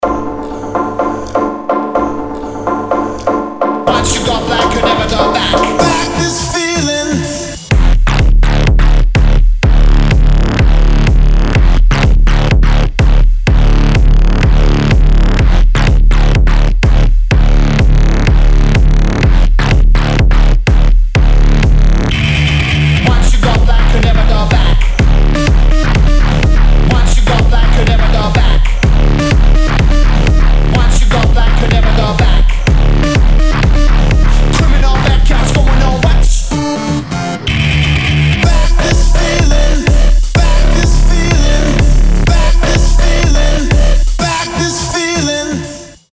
• Качество: 320, Stereo
жесткие
club
Bass
electro house